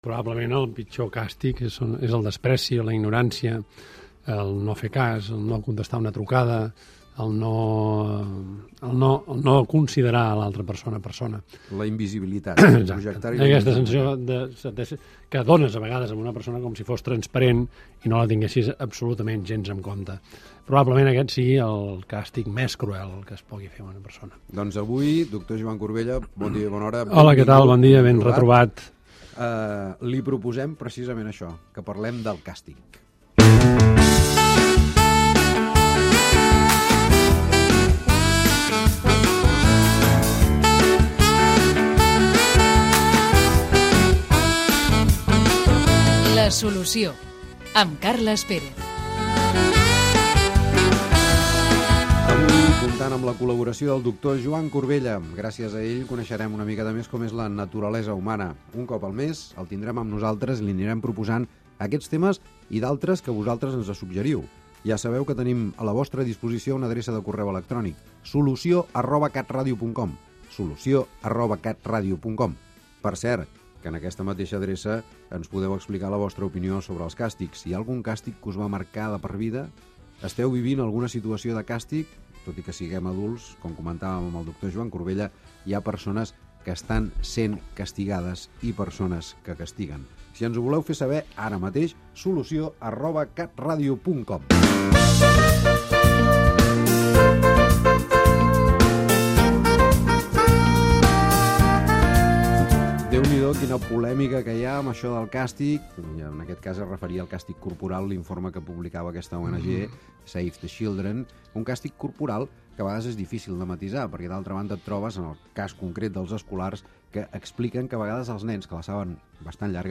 Reflexió inicial, presentació, indicatiu del programa, adreça electrònica, el doctor Joan Corbella parla dels càstigs